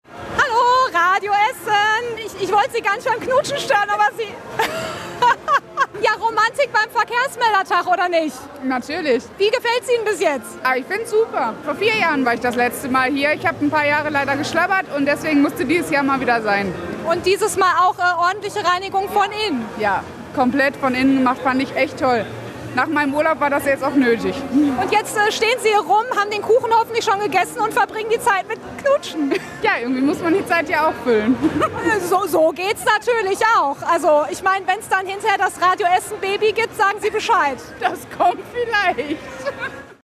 Der Radio Essen Verkehrsmeldertag 2019